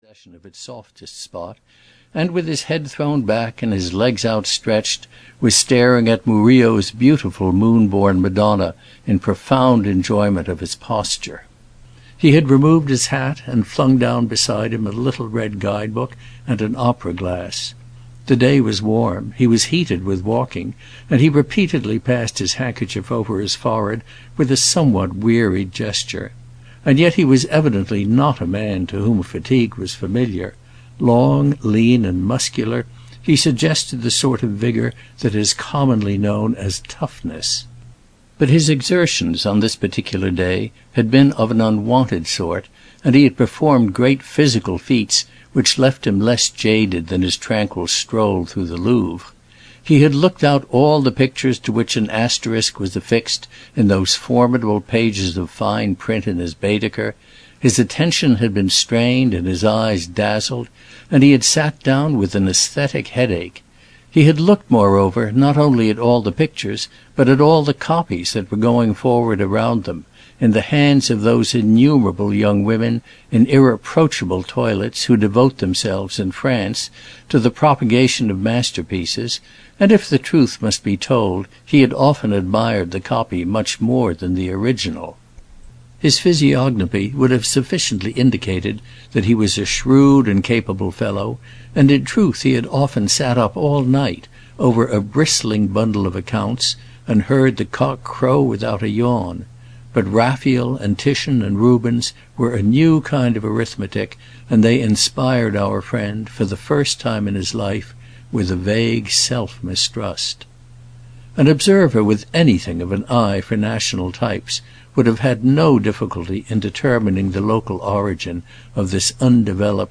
The American (EN) audiokniha
Ukázka z knihy